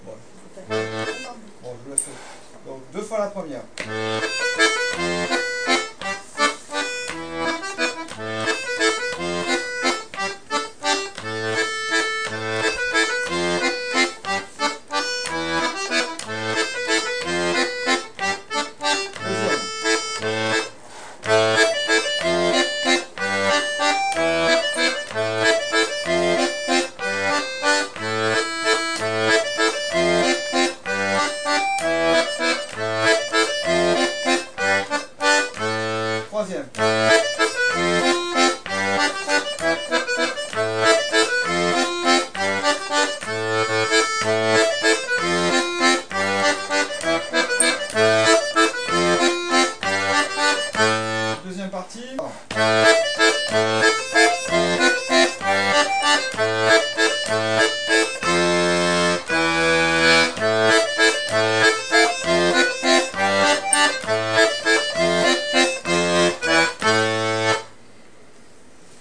l'atelier d'accordéon diatonique
2) Bourrée à 3 temps, l'Alambic
enregistrement de l'Alambic avec les 3 voix (première partie)